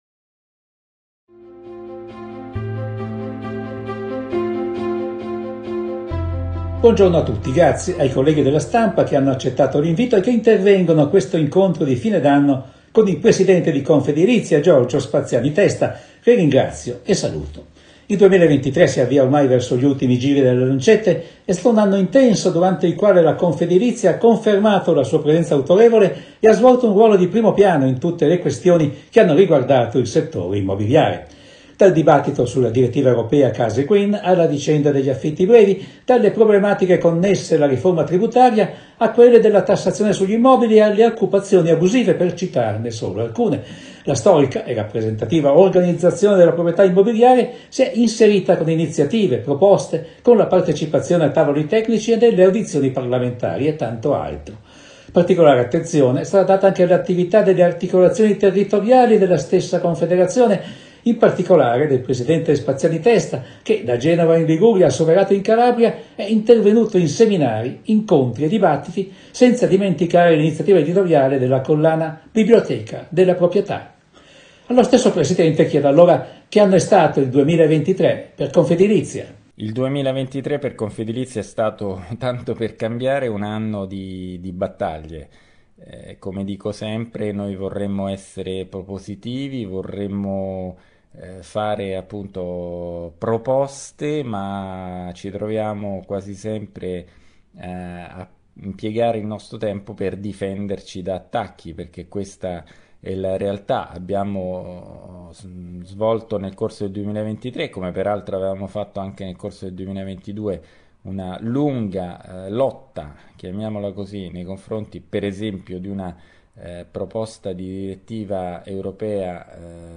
Incontro stampa di fine anno